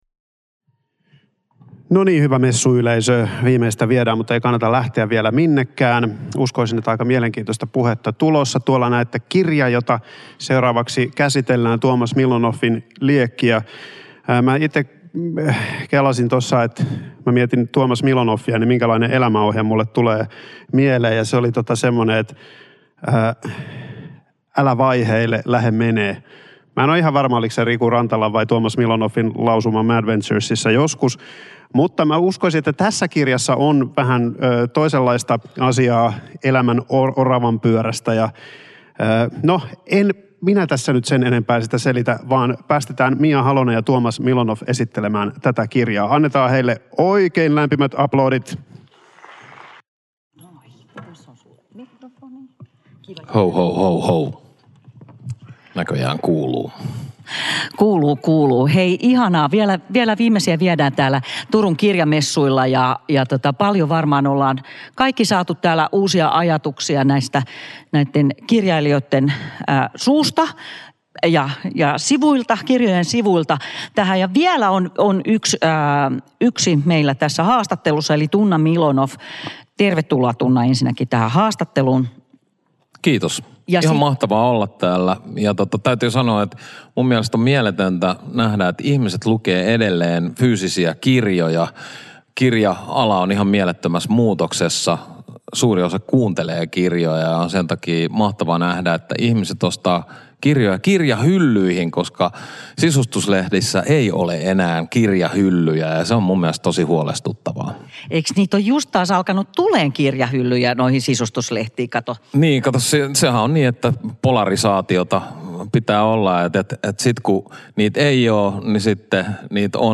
Tuomas Milonoff kirjoittaa väkevästi ja satuttavasti oravanpyörän kitkasta ja sen jalkoihin jäämisestä. Turun Kirjamessuilla